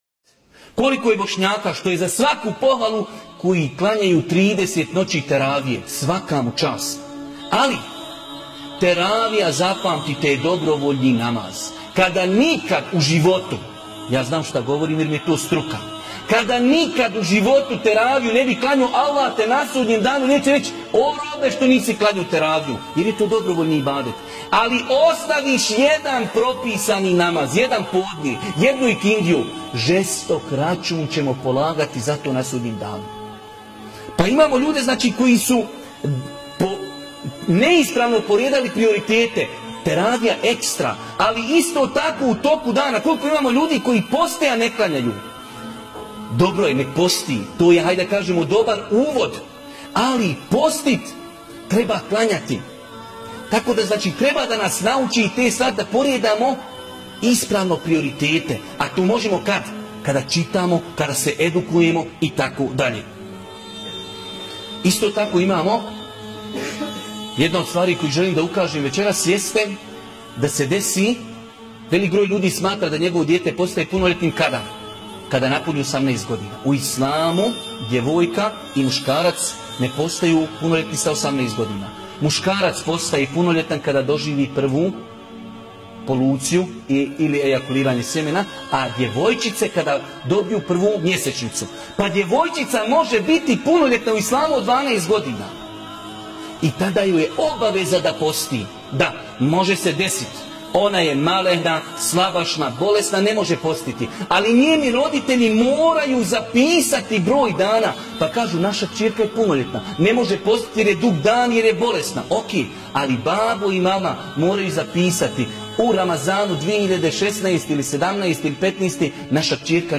Odgovor na pitanje nalazi se u kratkom predavnjau na linku ispod, sa početkom na 1,02 min.